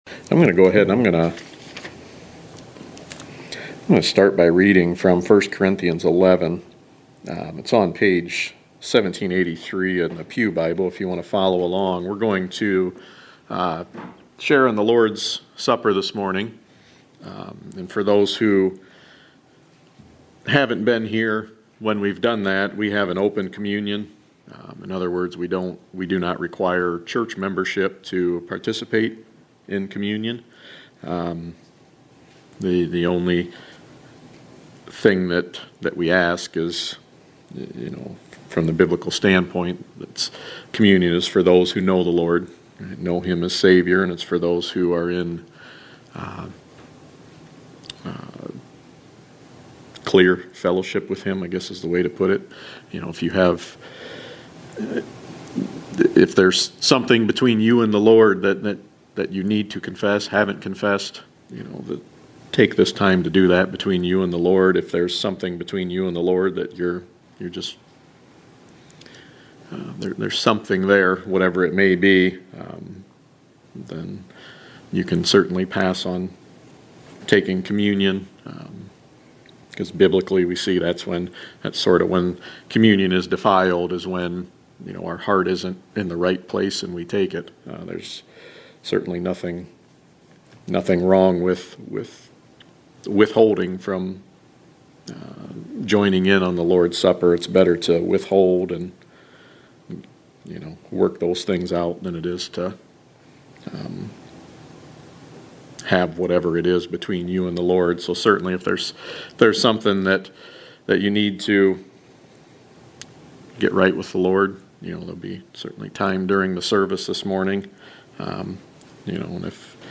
The Gospel of Matthew Series (25 sermons)